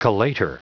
Prononciation du mot collator en anglais (fichier audio)
Prononciation du mot : collator